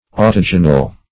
Autogeneal \Au`to*ge"ne*al\, a. Self-produced; autogenous.